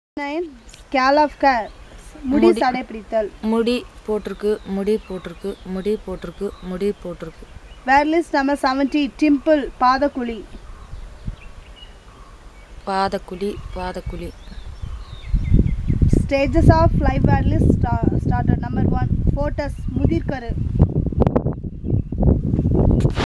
NotesThis is an elicitation of words about human body parts, using the SPPEL Language Documentation Handbook.